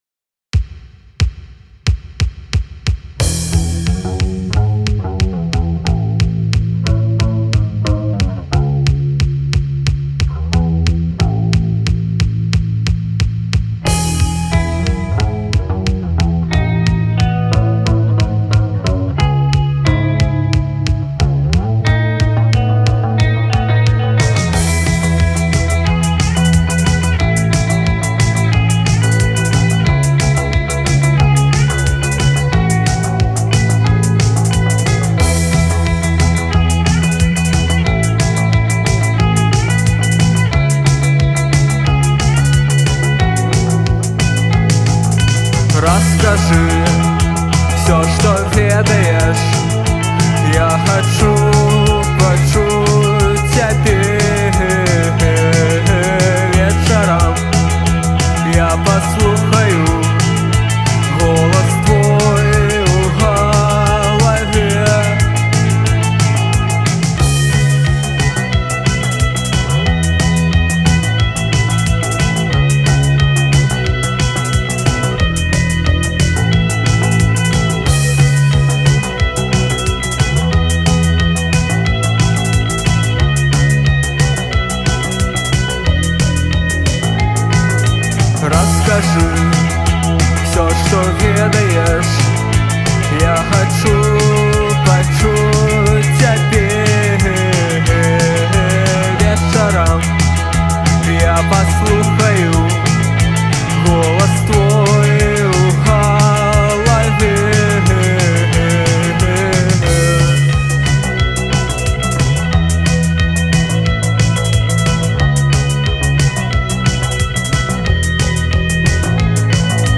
пост-панк